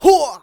xys普通3.wav 0:00.00 0:00.44 xys普通3.wav WAV · 38 KB · 單聲道 (1ch) 下载文件 本站所有音效均采用 CC0 授权 ，可免费用于商业与个人项目，无需署名。
人声采集素材